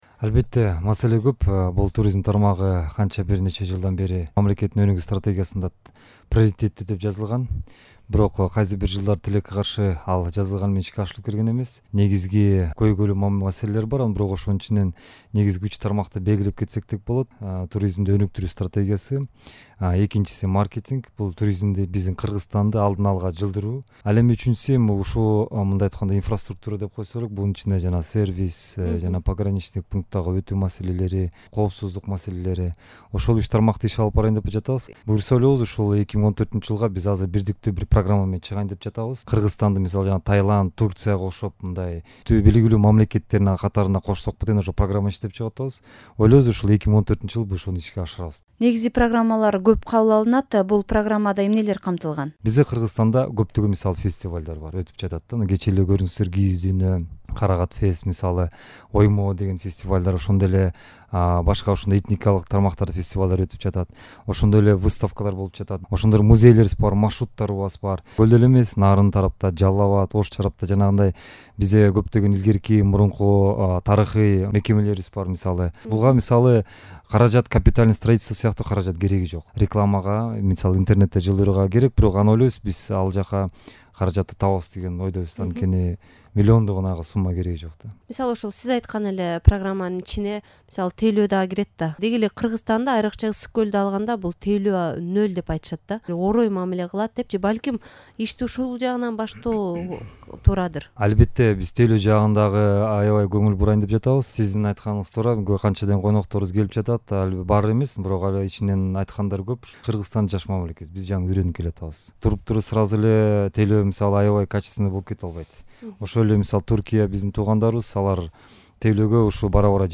Кыргызстандын жаңы туристтик саясаты кандай болот? "Азаттыктын" суроолоруна Маданият, маалымат жана туризм министринин орун басары, туризм департаментинин жаңы башчысы Максат Чакиев жооп берди.